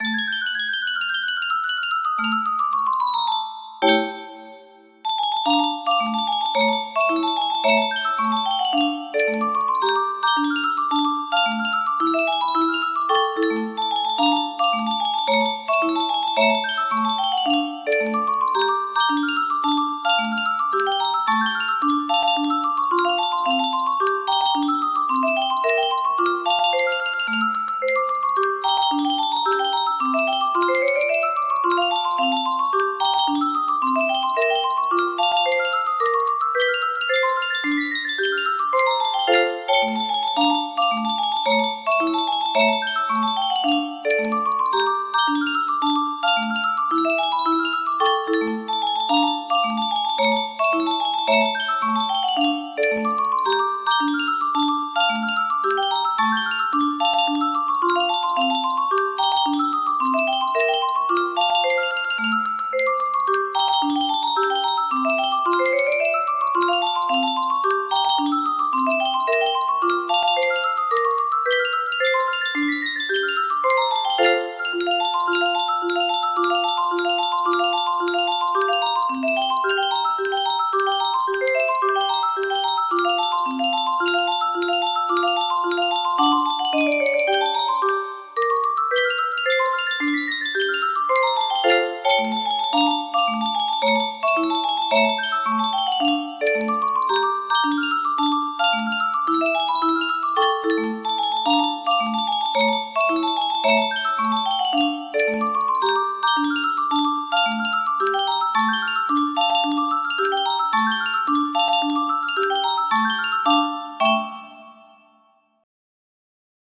Brazilianchoro song